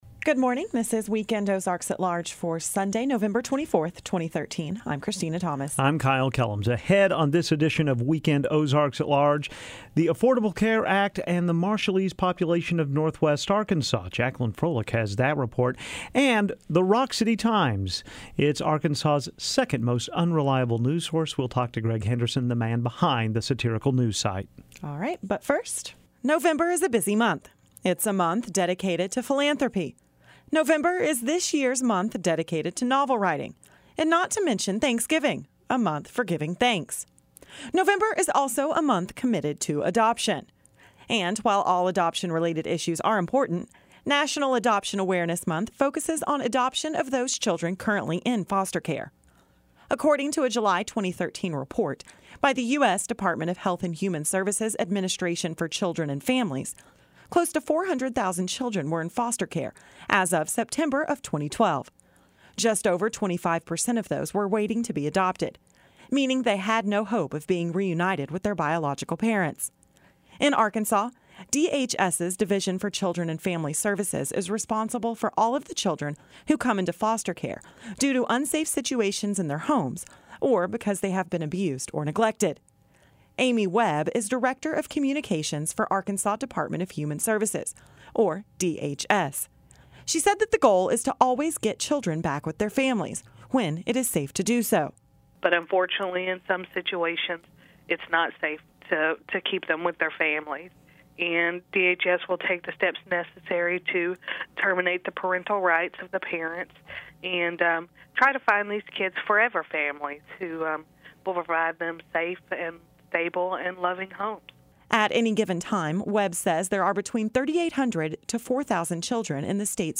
report. Plus, the Rock City Times is Arkansas' second most unreliable news source.